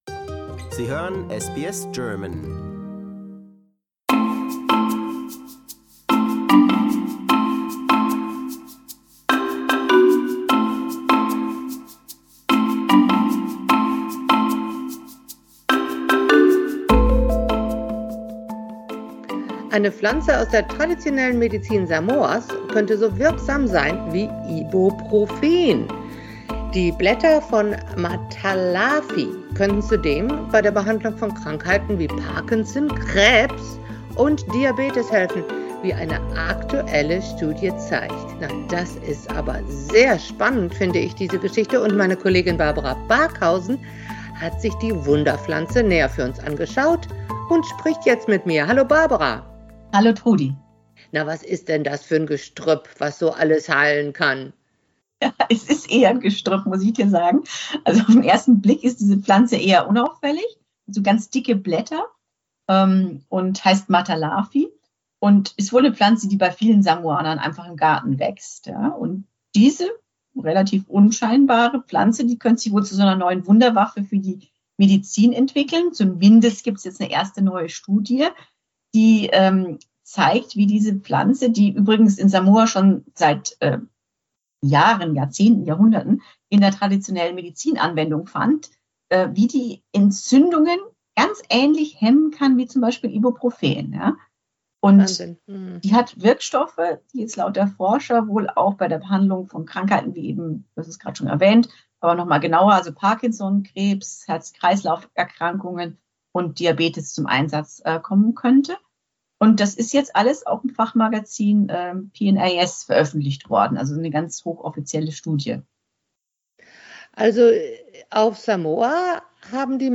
ist jetzt bei uns am Telefon